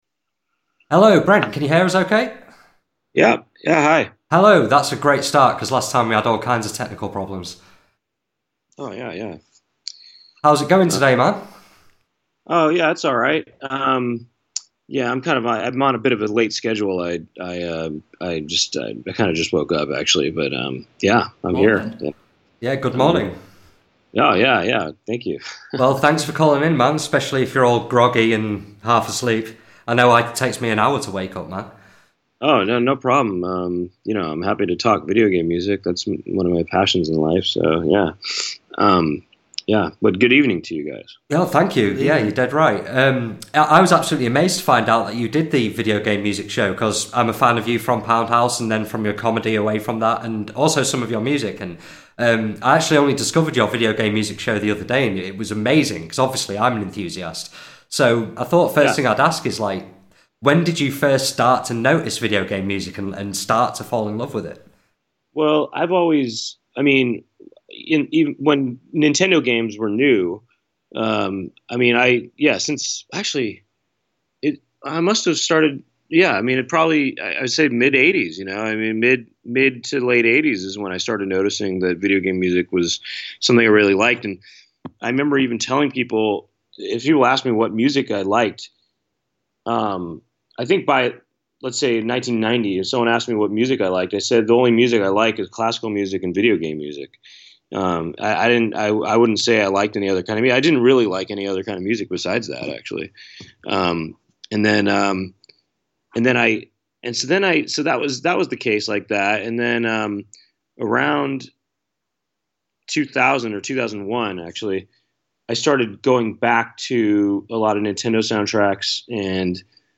BONUS - LIVE Brent Weinbach Interview (05/04/19)